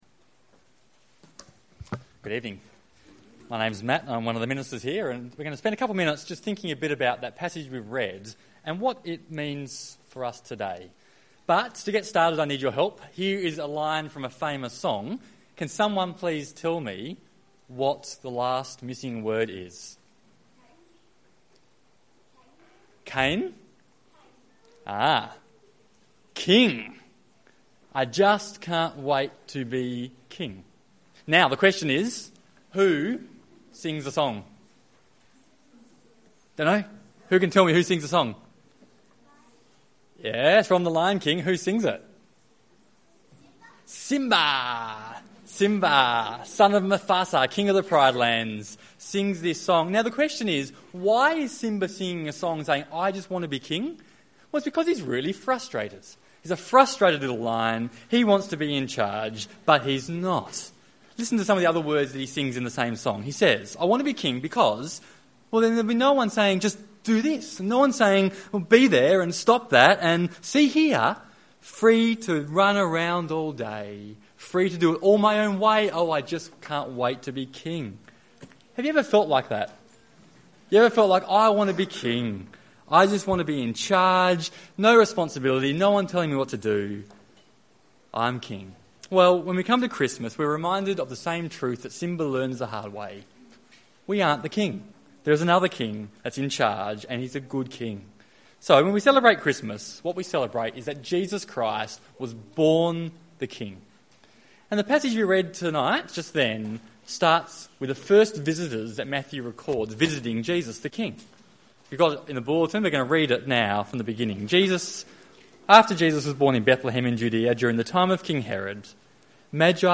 They acknowledge the greatness of Jesus. Listen to talk